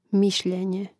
mȉšljēnje mišljenje